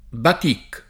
batic [ bat & k ] o batik [ id. ] s. m.